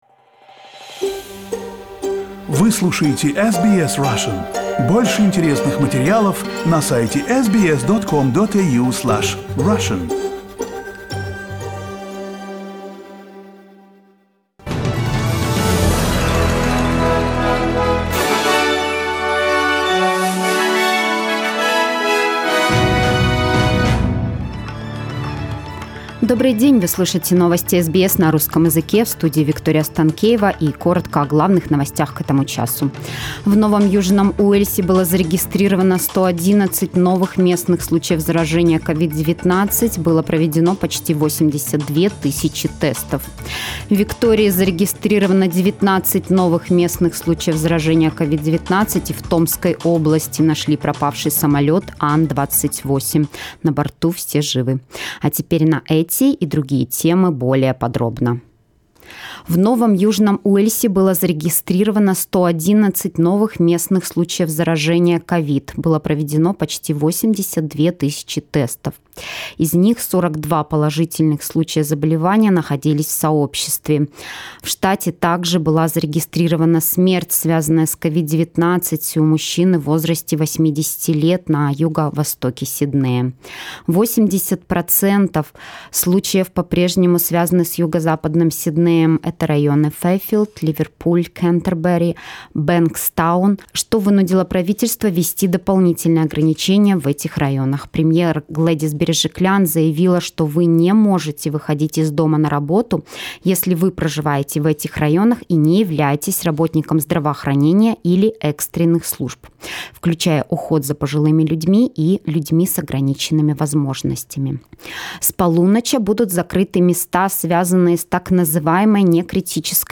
SBS news in Russian - 17.07